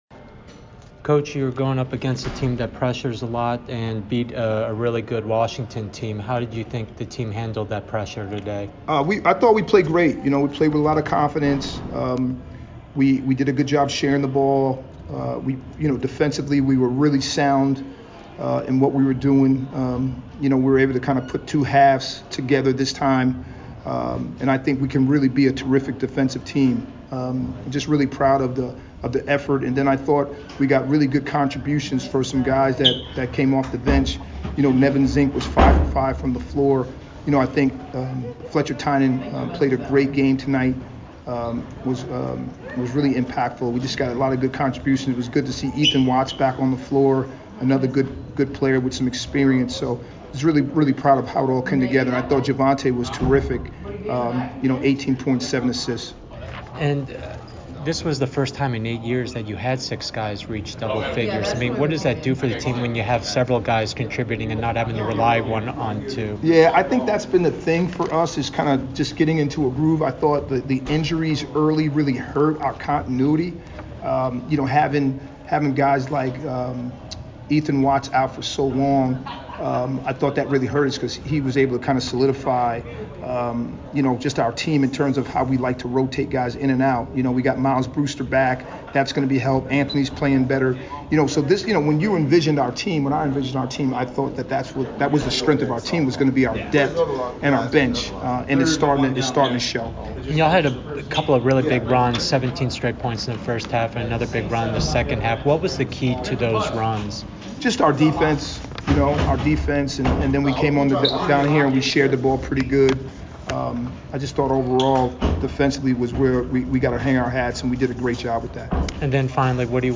NIU MBB Postgame Interview